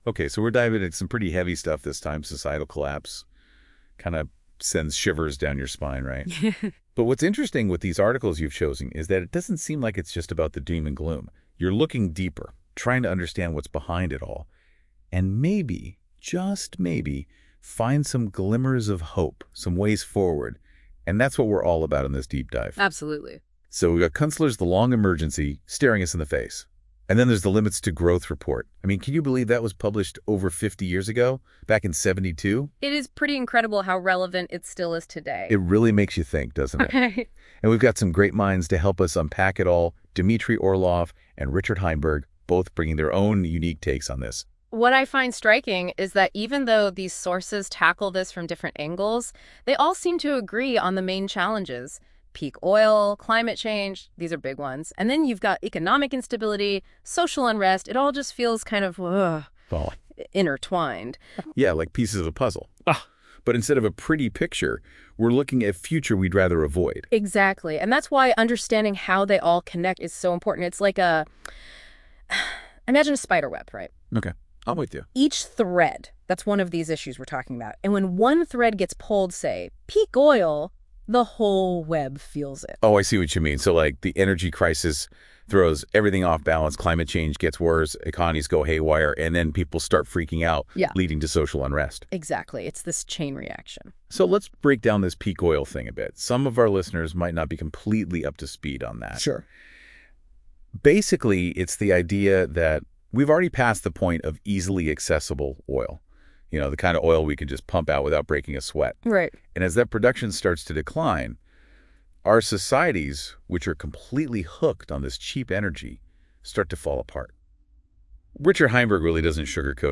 Collapse Details The voices discuss the interconnected crises facing humanity, including peak oil, climate change, economic instability, and social and political unrest.